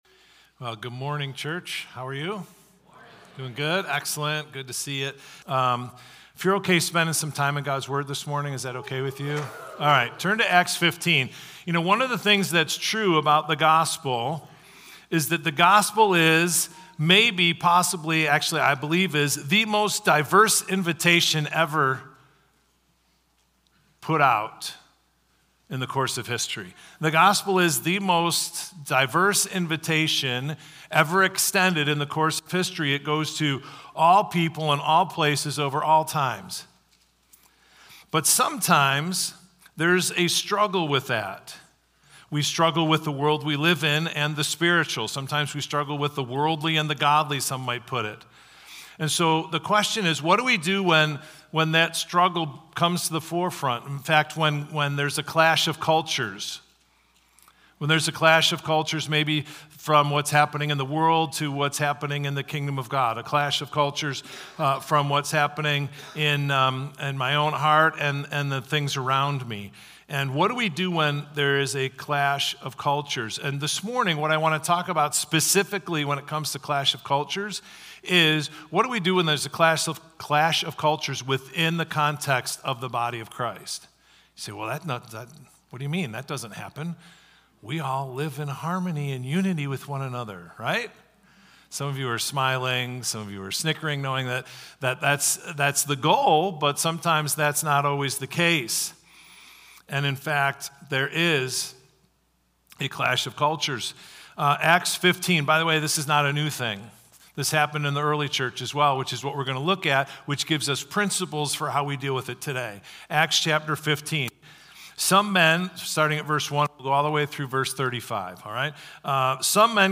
Victor Community Church Sunday Messages / Clash of Cultures: The Freedom of Christ (June 2nd, 2024)